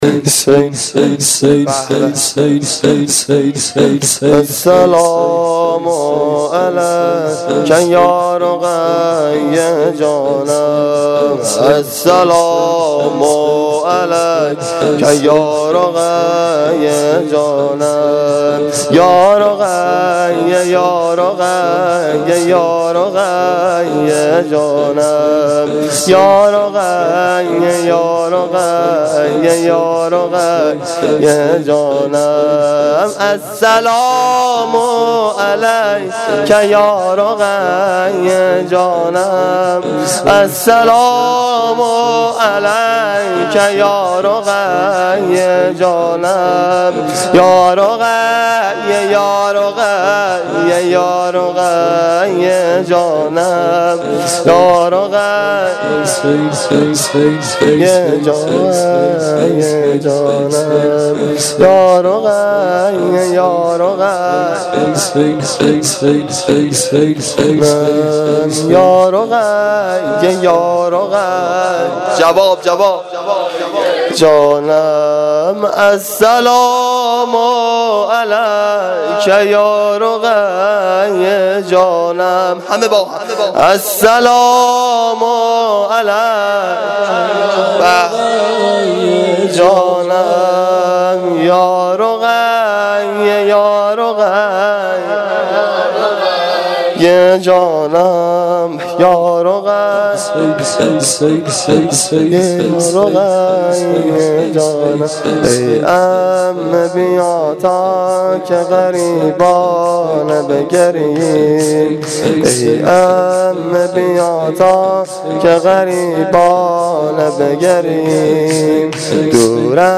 شور شب سوم محرم الحرام 1395